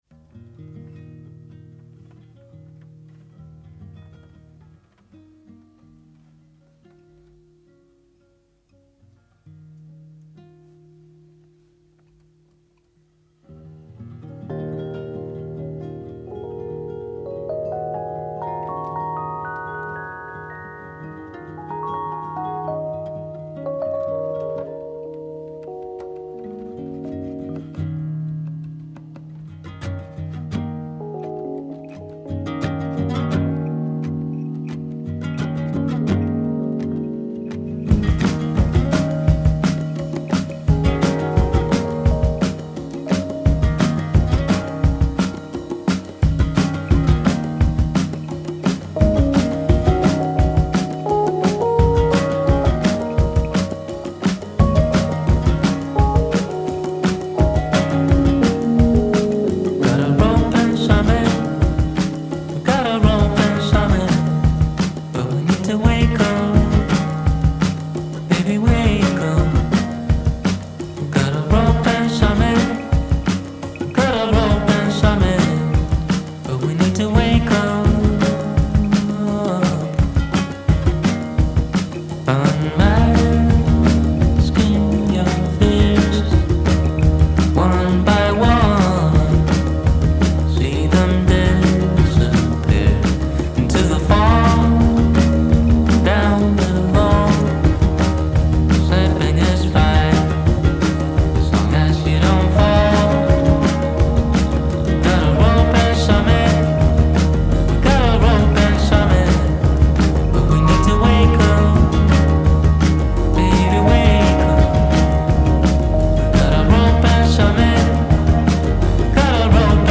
Swedish folk rock